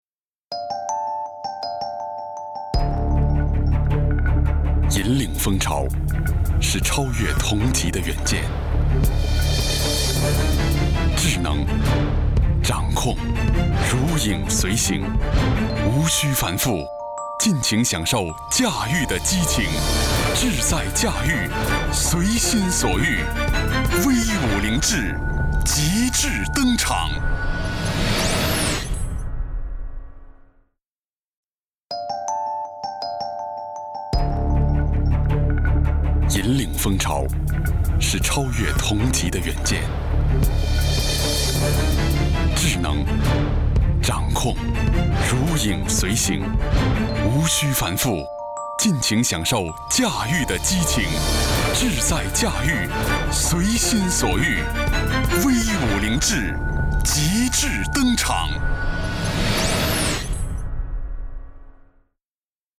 • 男11 国语 男声 广告_东南菱致V5 大气浑厚磁性|沉稳|积极向上|时尚活力